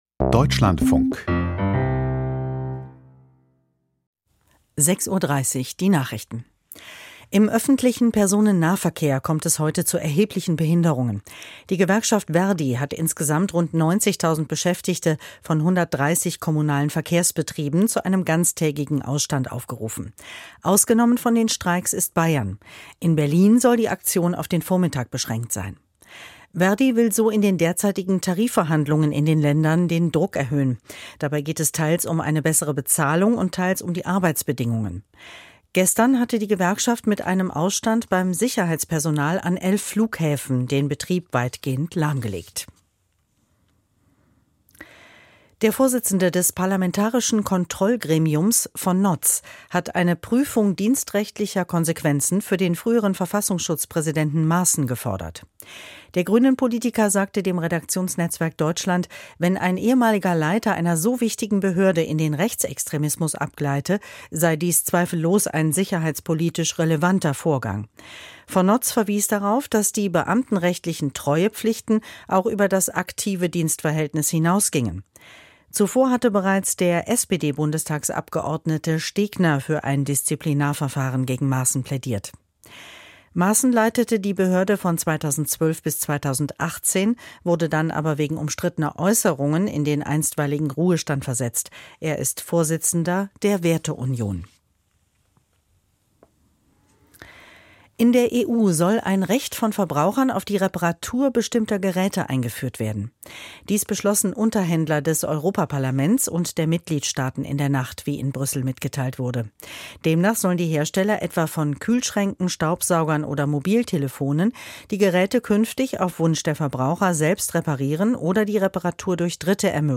Debatte um Haushaltskompromiss hält an: Interview Dirk Wiese, SPD, Fraktionsvize - 18.12.2023